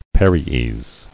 [pa·ri·es]